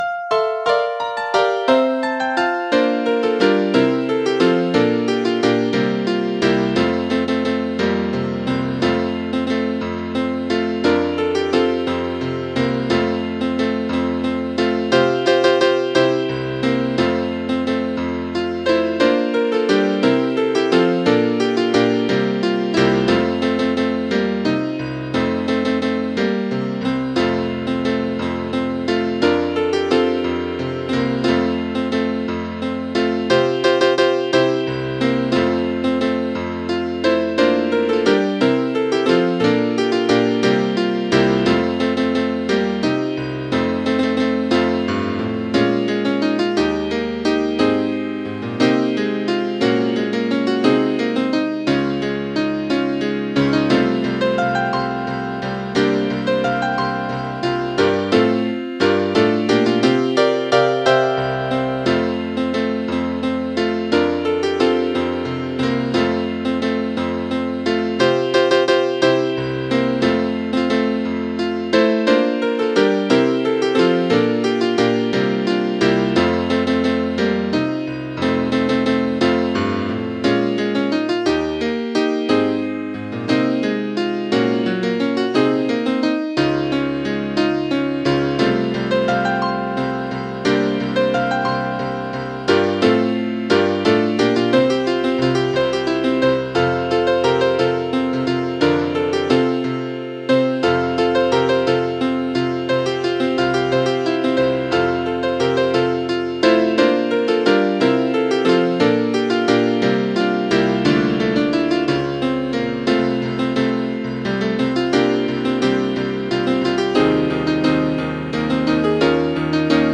ALTAR-DE-DOR-negativ.mp3